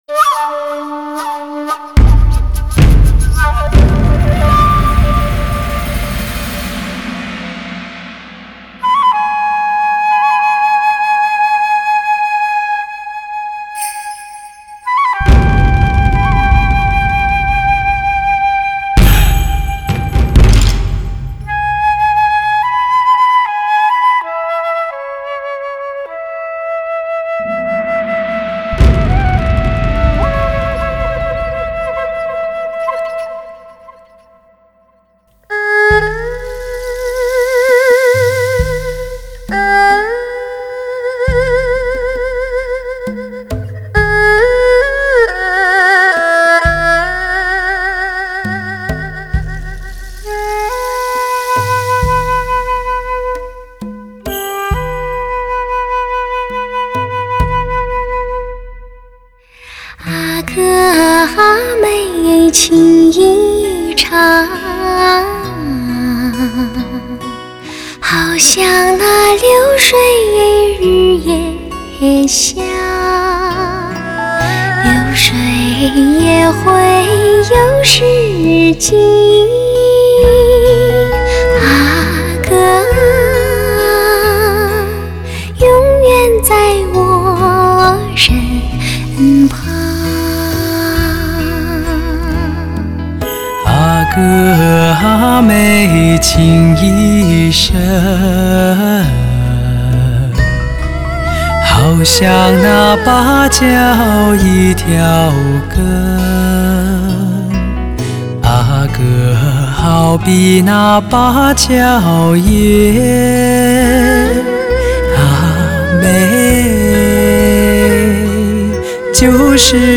中国第一对发烧对唱极品
全频连贯和动态表现更佳，人声音色更柔顺。